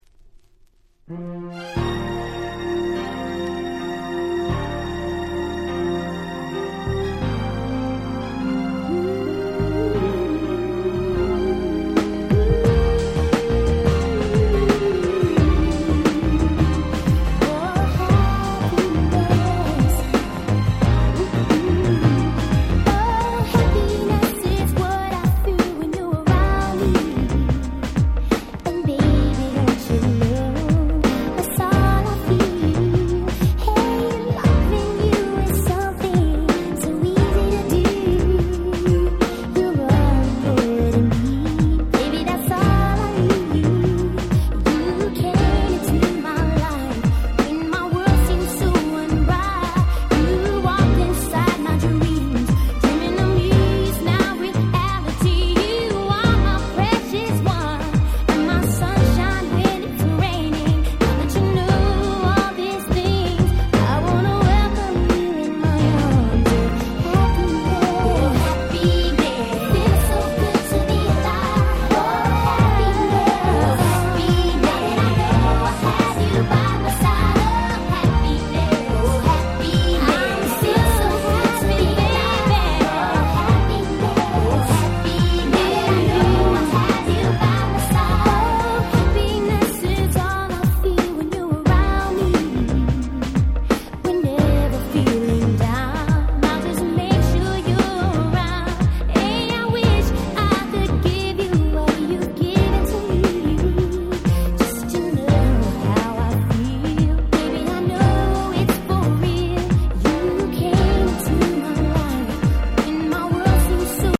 94' Very Nice R&B !!